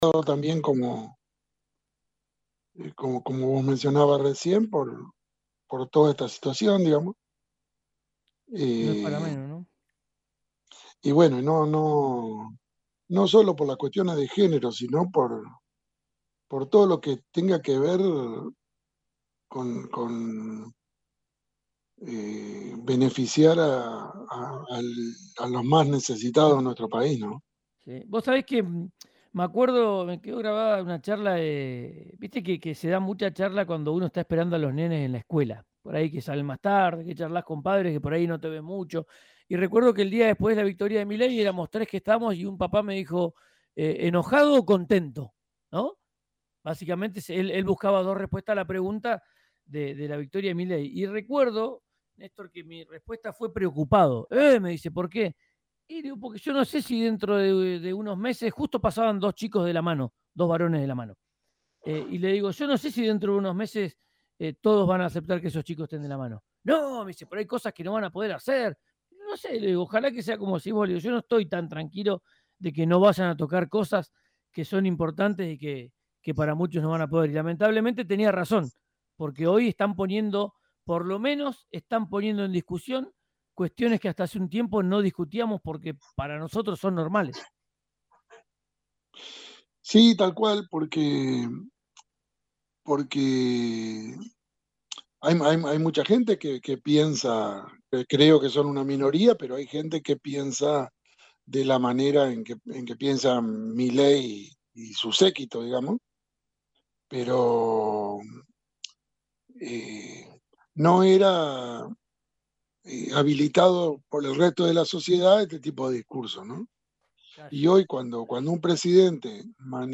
en el aire de RÍO NEGRO RADIO